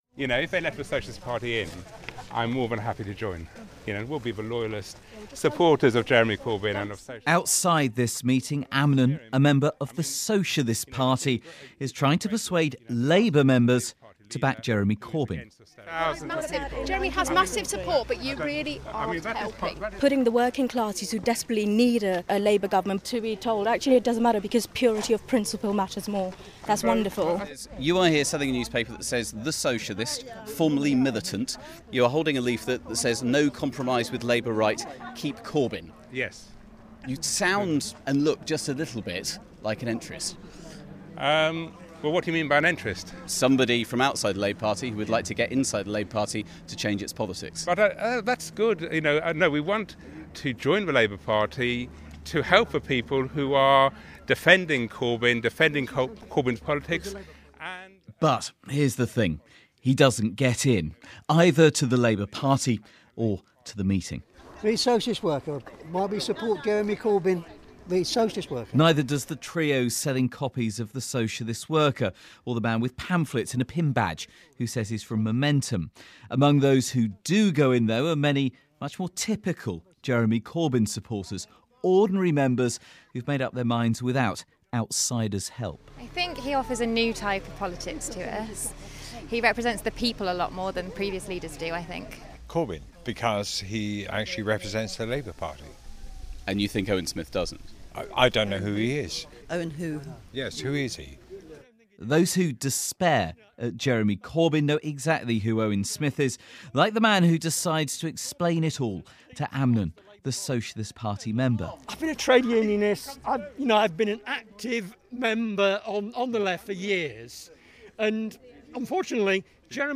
Walthamstow Labour meeting